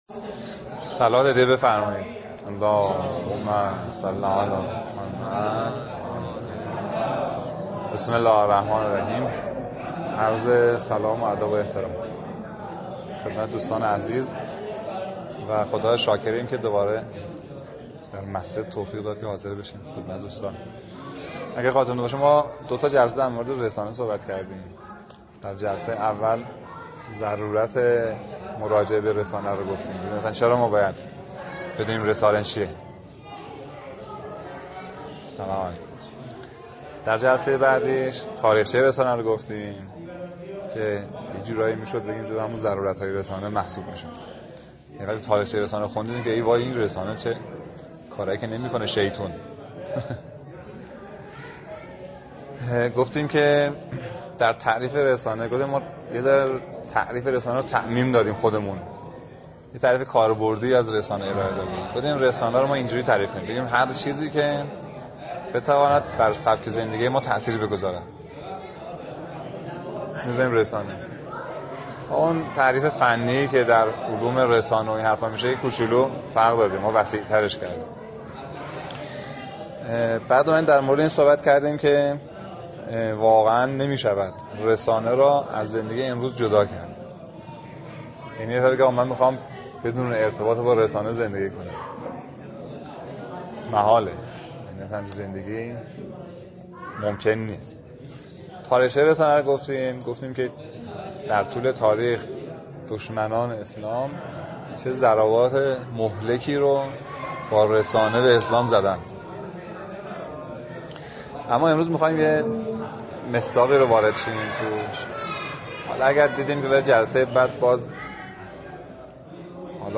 گفتار | درس هایی از فلسفه اسلامی | نقد نظریه بازی های زبانی لودویگ ویتگنشتاین